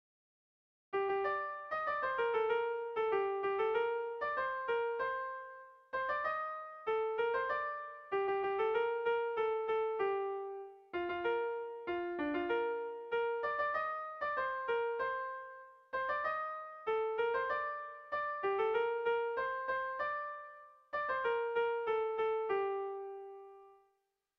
Zortziko ertaina (hg) / Lau puntuko ertaina (ip)
ABDB2